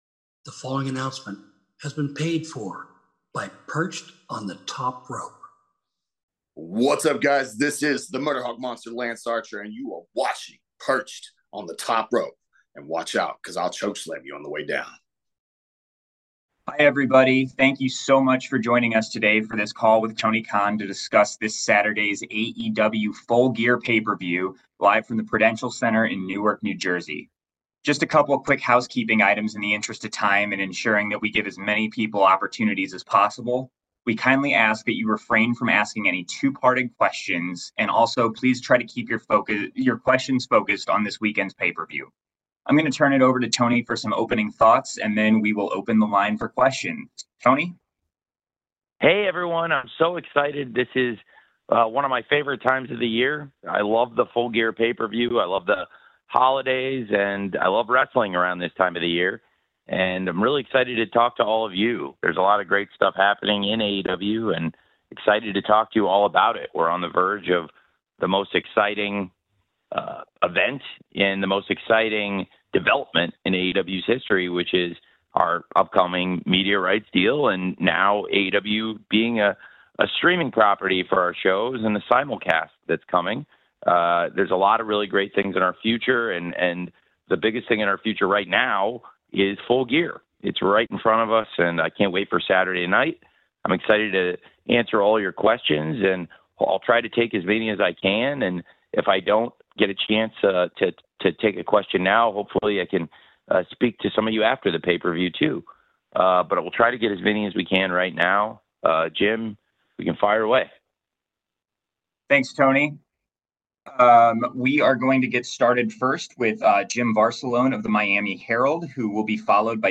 This Media Call has Tony Khan being asked a variety of topics about AEW Full Gear Pay Per View!